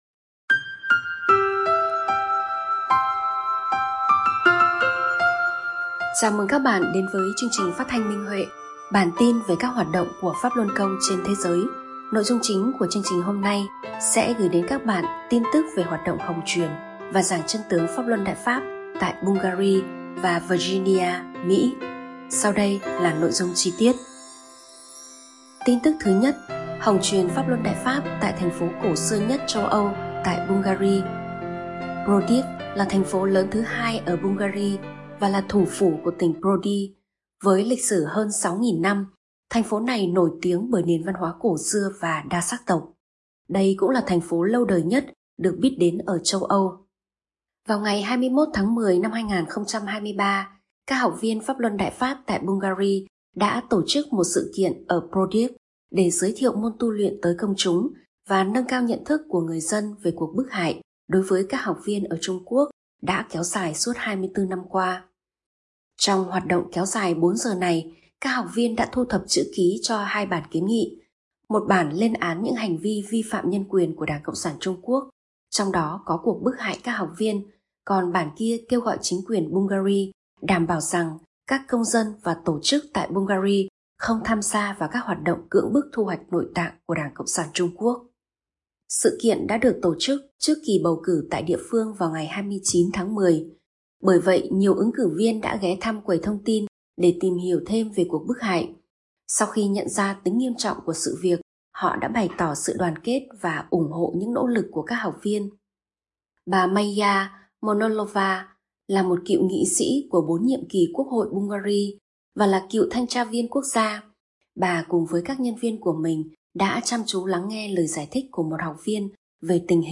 Chương trình phát thanh số 87: Tin tức Pháp Luân Đại Pháp trên thế giới – Ngày 06/11/2023